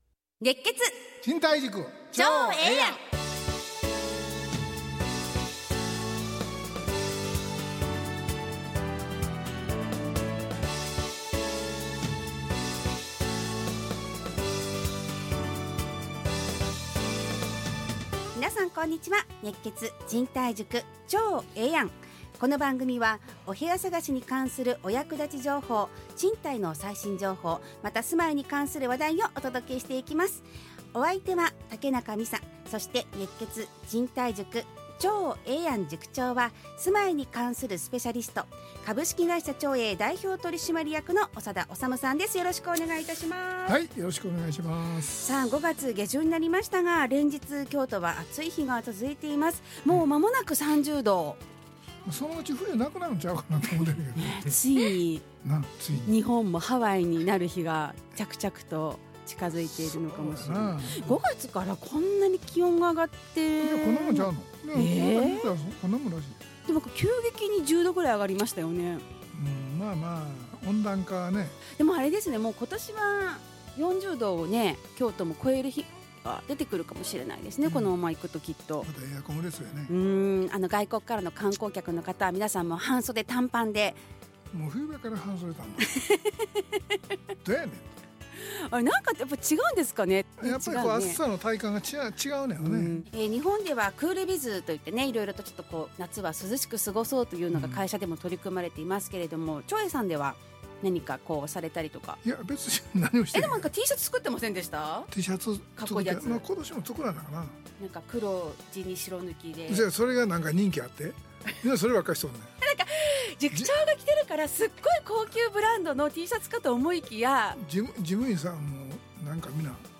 ラジオ放送 2025-05-23 熱血！